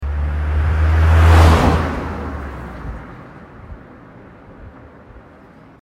Download Car Passing sound effect for free.
Car Passing